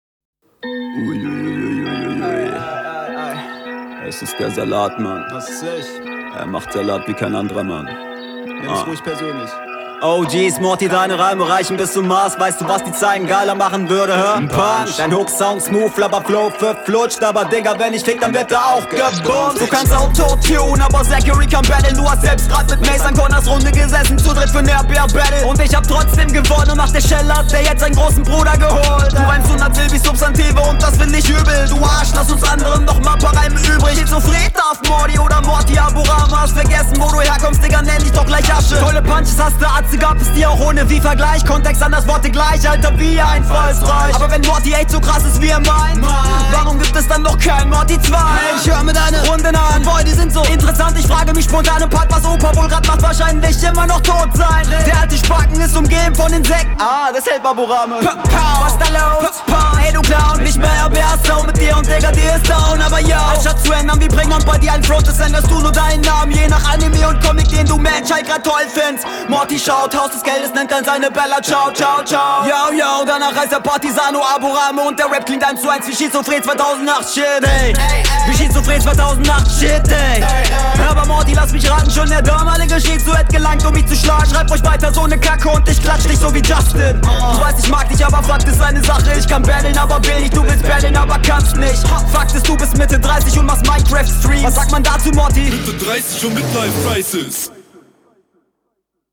Aiaiai, ein Orgelbeat.
Hier find ich dich stimmlich schon viel passender zum Beat (der übrigens mega schrecklich ist)!